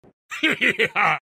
Heheheha_sound_affect.mp3